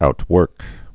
(out-wûrk)